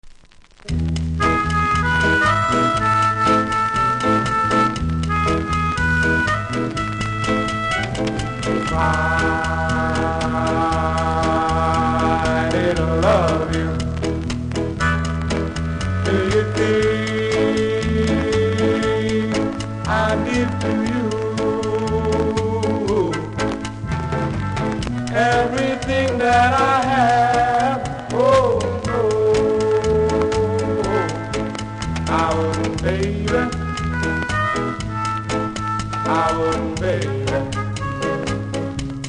プレスノイズありますので試聴で確認下さい。